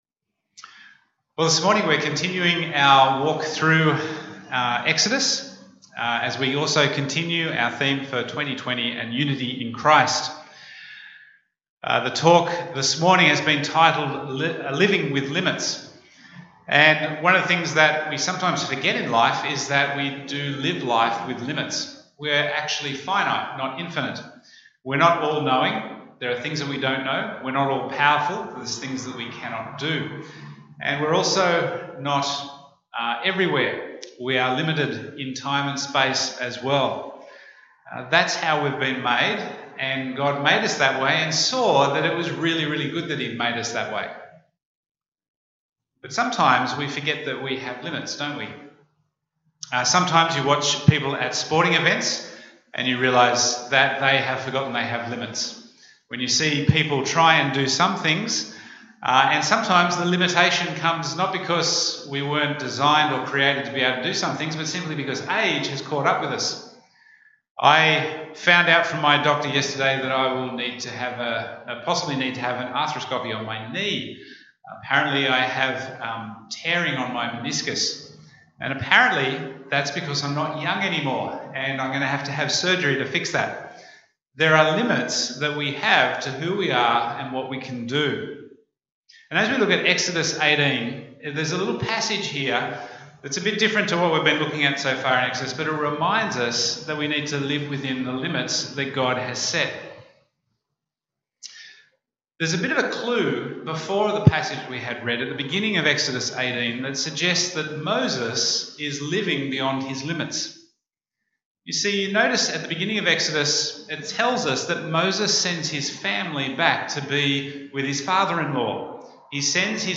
Bible Text: Exodus 18:13-27 | Preacher